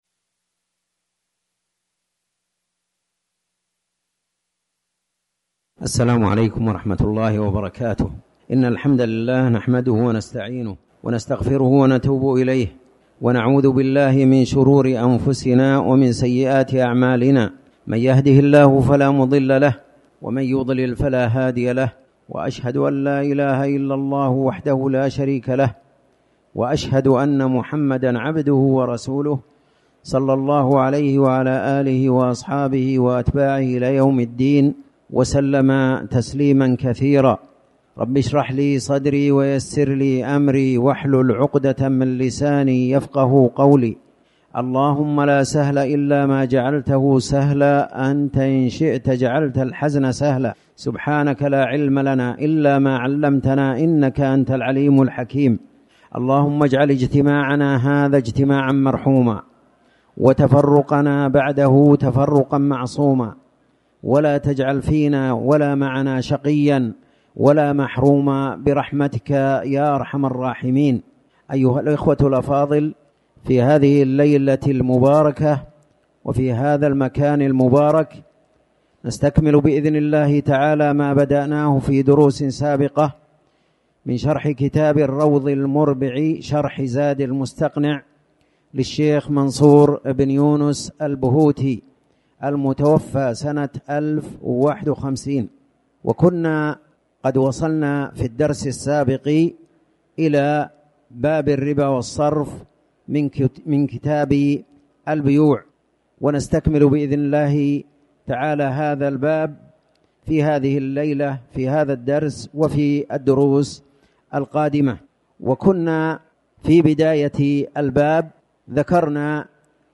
تاريخ النشر ٢٥ ربيع الثاني ١٤٤٠ هـ المكان: المسجد الحرام الشيخ